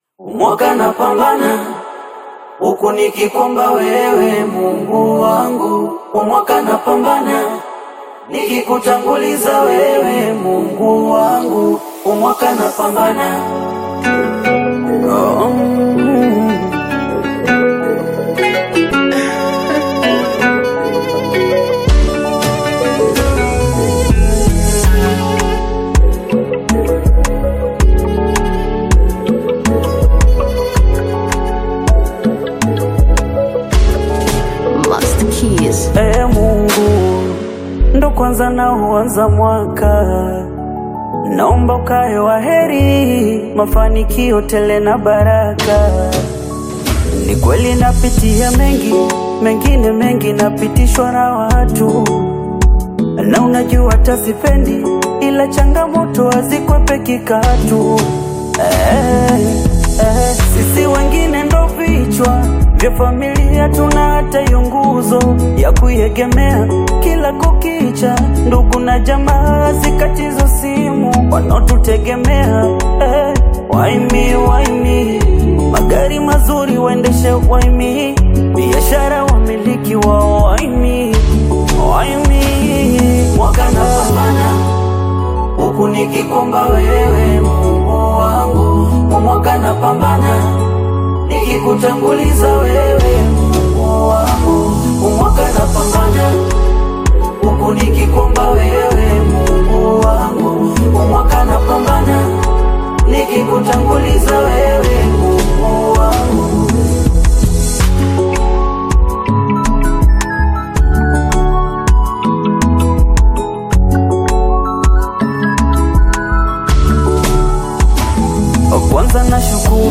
Bongo Flava music